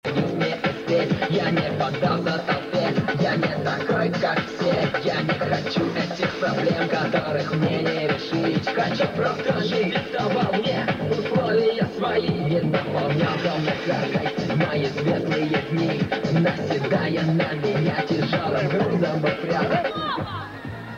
помогите распознать группу,исполняющую отрывок,в 97 году слышал её на кассетах,надеюсь на помощь,спб
рус.рэп.mp3